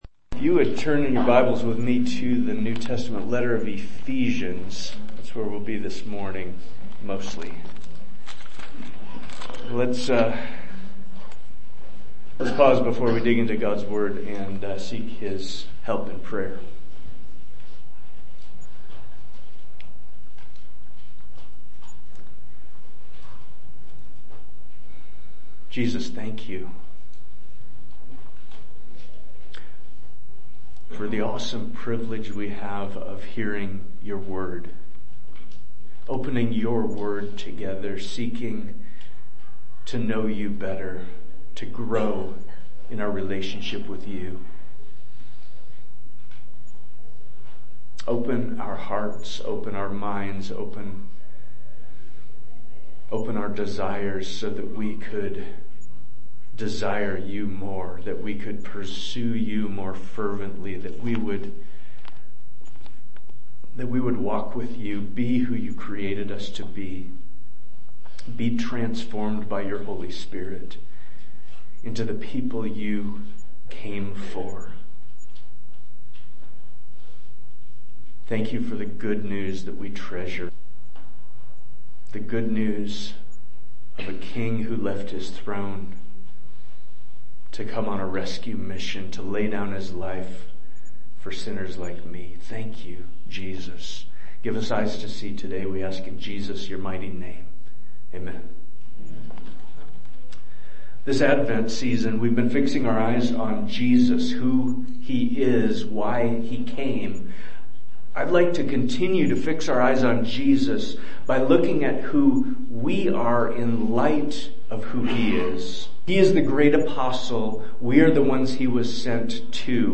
Sermons | Ephraim Church of the Bible